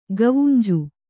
♪ gavunju